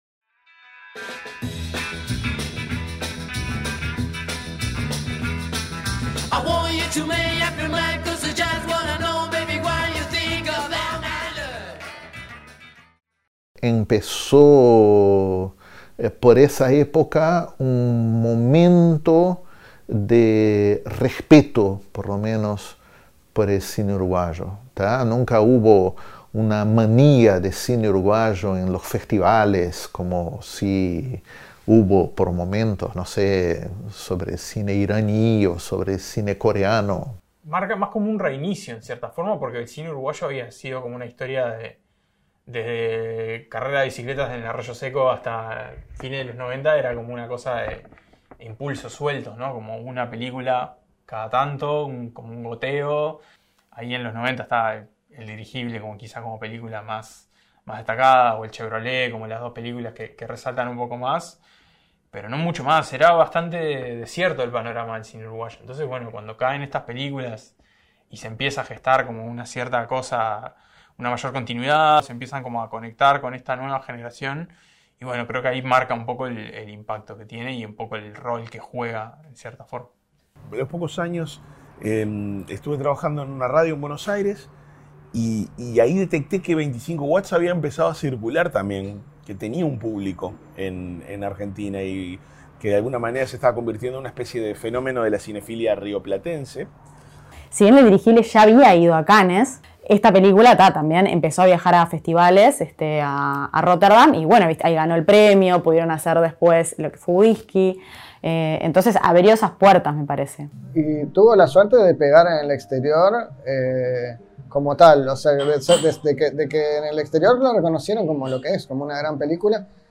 Conversamos con seis críticos del medio local, para conocer sus opiniones y reacciones a la película, los aspectos positivos y negativos, por qué es una película importante para el cine uruguayo y mucho más.
La música inicial pertenece al soundtrack de la película: Make Up Your Mind, interpretada por Los Mockers.